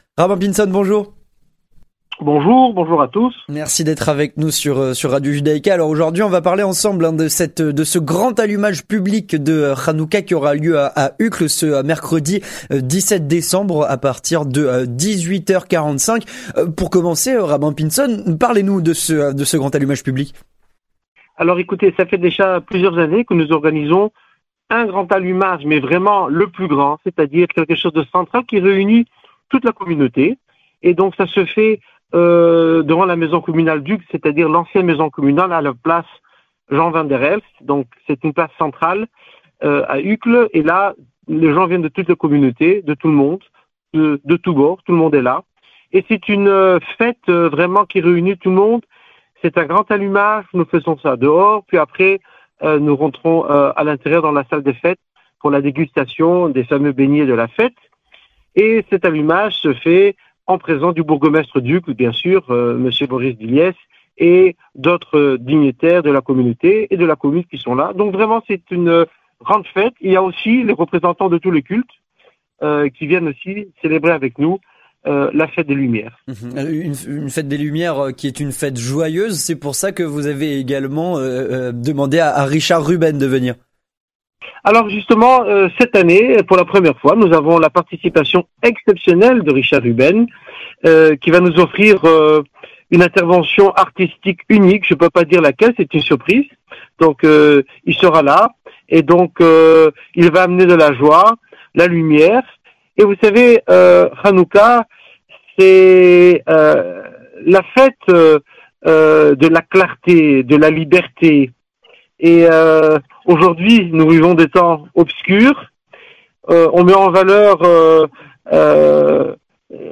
L'interview Communautaire - Allumage des bougies de Hanoucca.